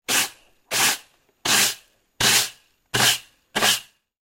Звуки метлы
Звук жесткой метлы при подметании